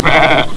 1 channel
snd_4008_Lamb.wav